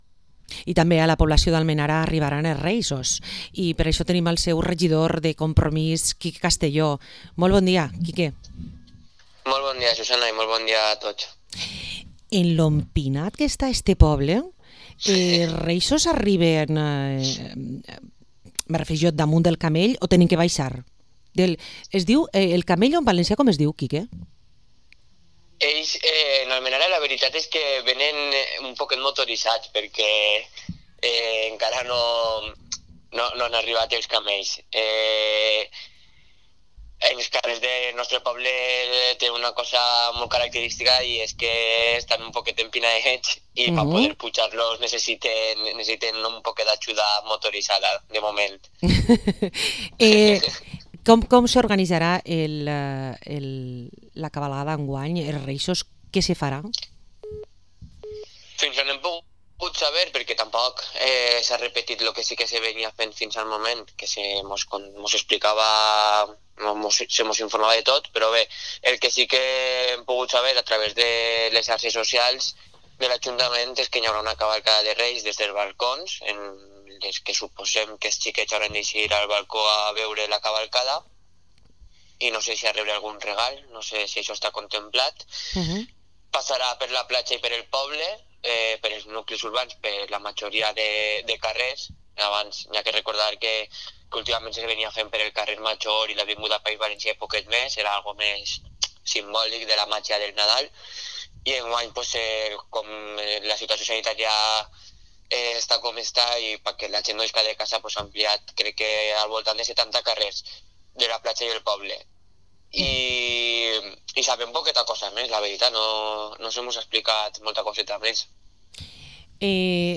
Entrevista al concejal de Compromís per Almenara, Quique Castelló